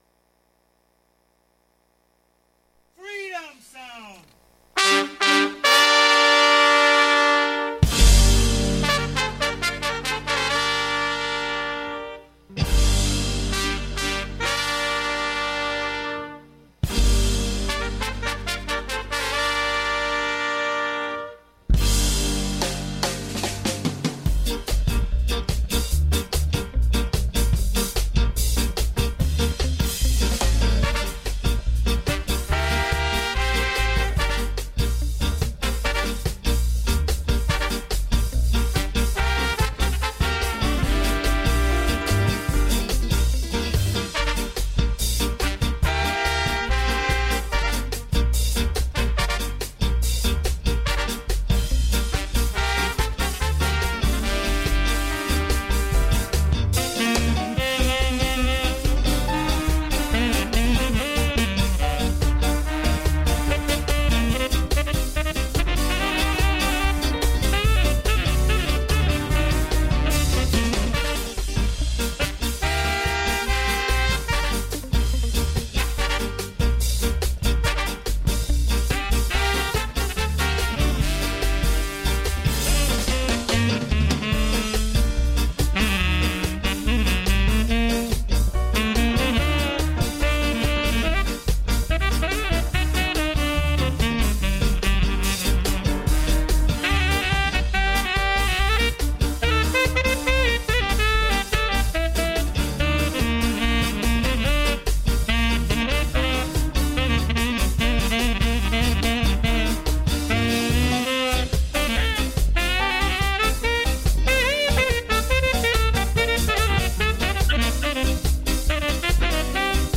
Aquesta setmana recuperem un programa especial de 2020 per celebrar la diada de Sant Jordi. Una petita selecció d’algunes de les cançons més romàntiques, sobretot de l’època daurada del rocksteady, uns dels nostres estils preferits.